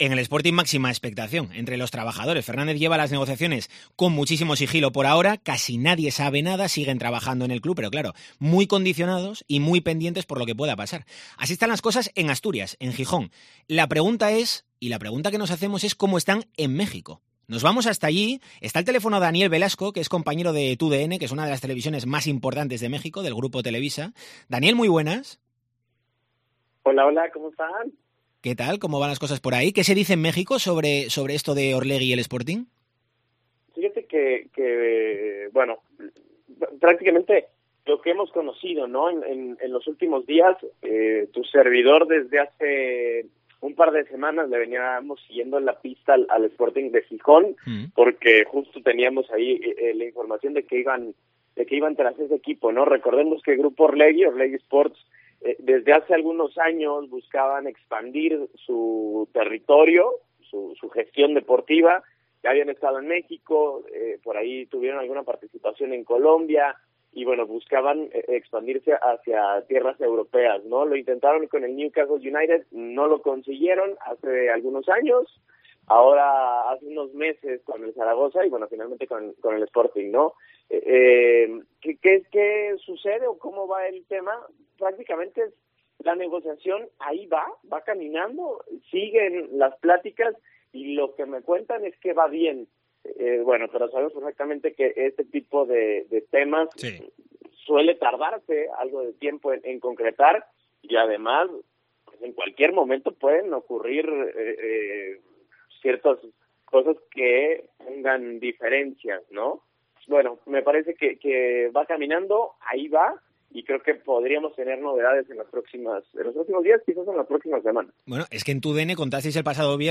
ENTREVISTA DCA